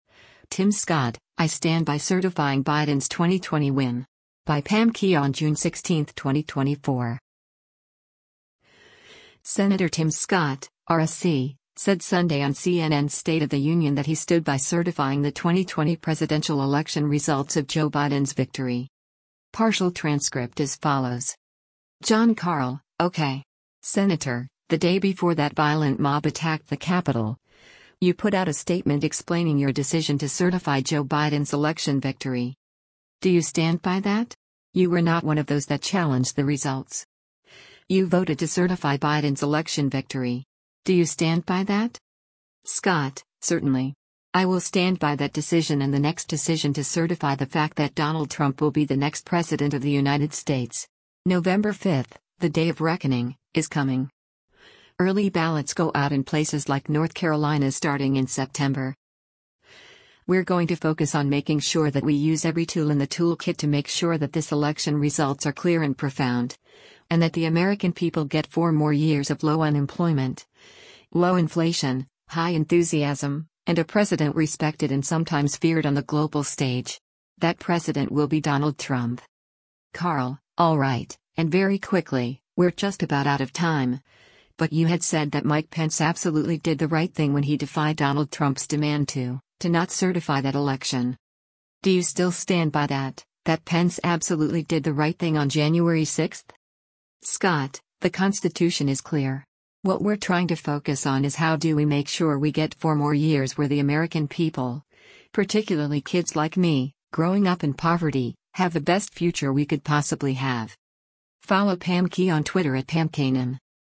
Senator Tim Scott (R-SC) said Sunday on CNN’s “State of the Union” that he stood by certifying the 2020 presidential election results of Joe Biden’s victory.